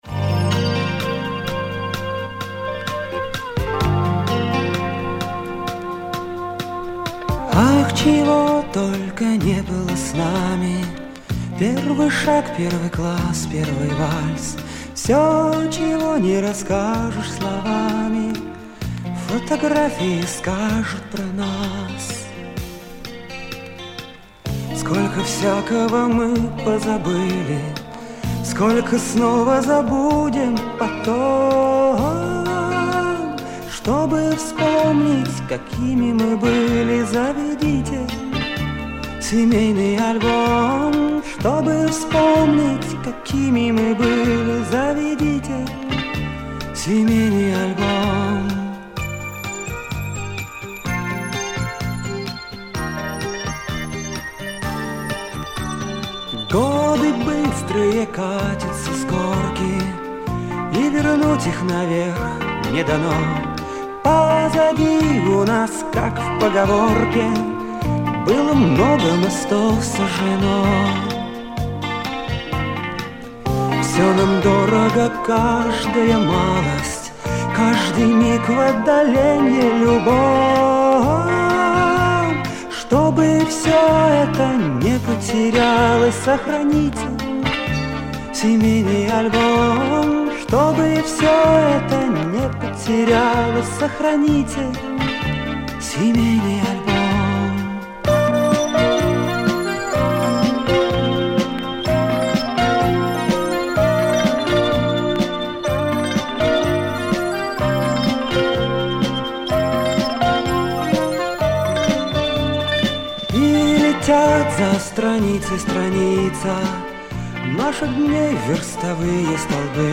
клавишные
гитара
ударные
бас-гитара